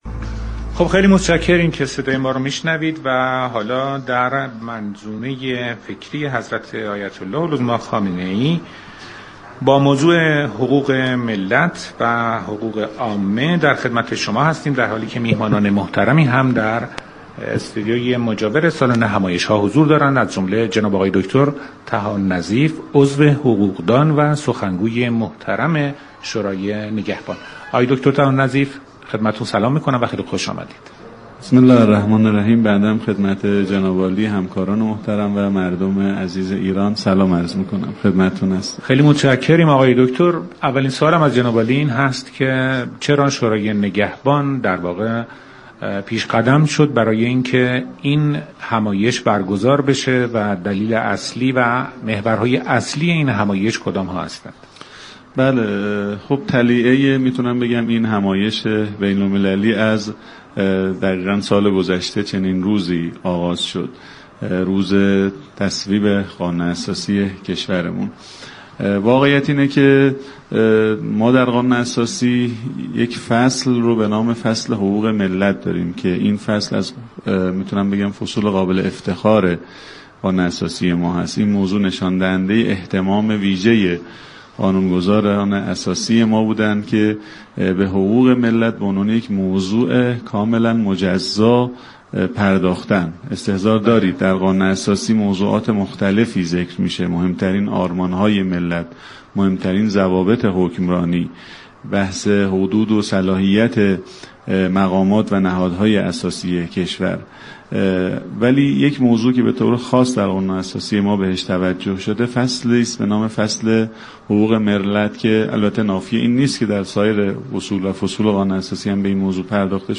سخنگوی شورای نگهبان در برنامه ی ژرفا رادیو ایران گفت: فصل مربوط به حقوق ملت جزء فصول قابل افتخار قانون اساسی ایران است.